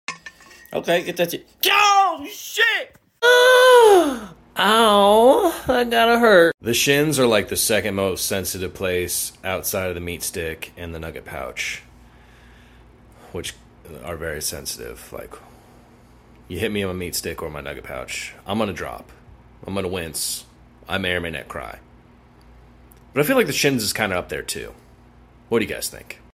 Ouch Sound Effects Free Download